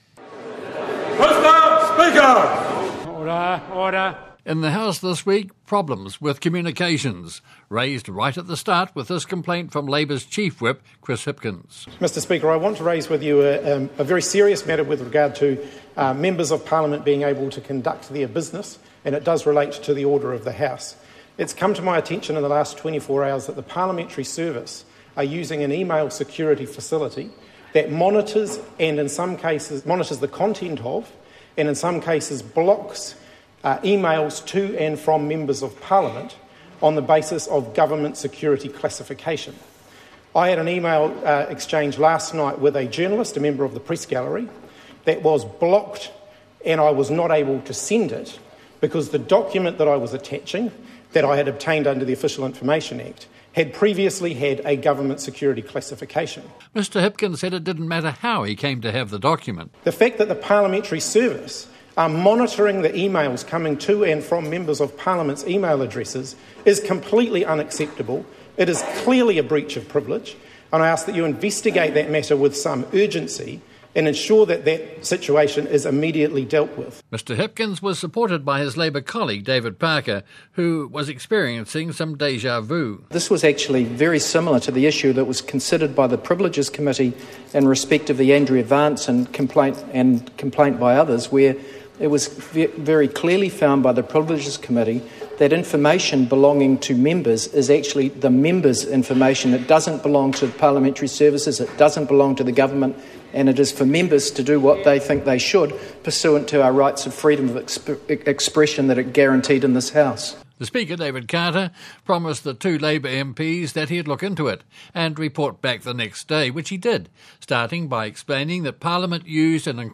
Extras include more audio from the committee rooms, Environment Minister, Nick Smith, questioned about his handling over the Government’s proposal to establish a marine sanctuary around the […]